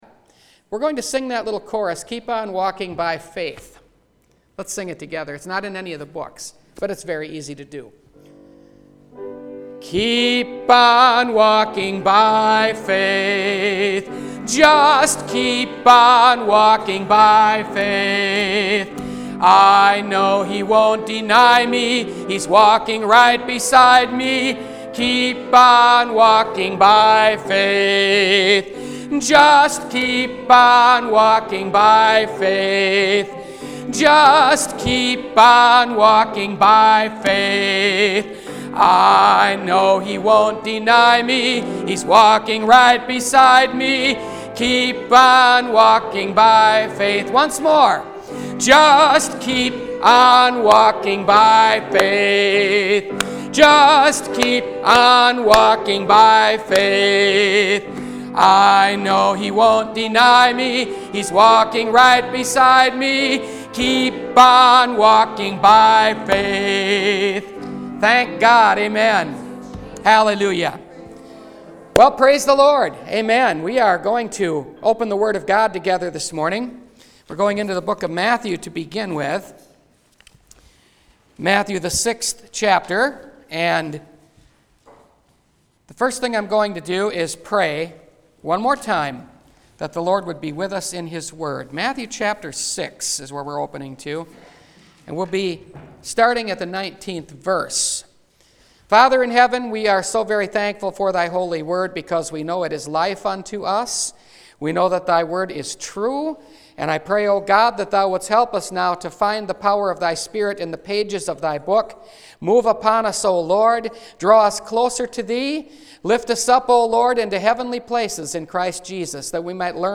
Your Highest Priority – Last Trumpet Ministries – Truth Tabernacle – Sermon Library
Classic Sermons Service Type: Sunday Morning « They Turned The World Upside Down